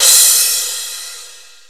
Crashes & Cymbals
Cardiak Crash.WAV